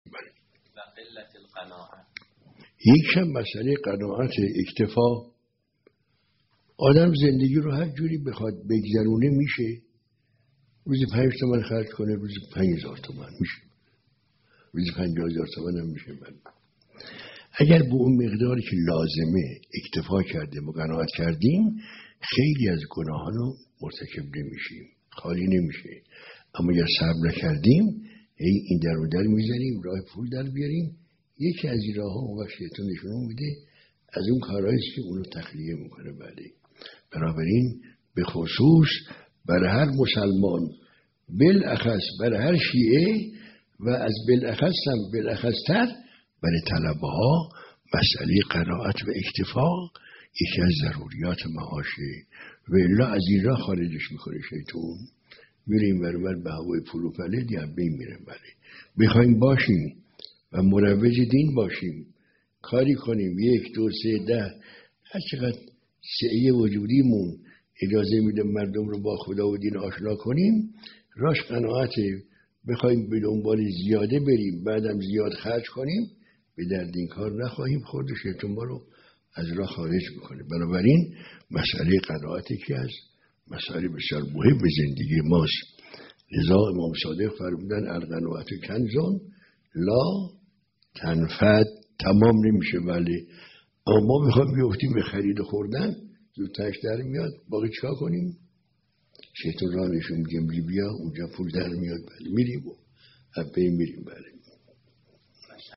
درس اخلاق | قناعت و زیاده طلبی؛ دو مقوله سعادت و شقاوت معنویت زندگی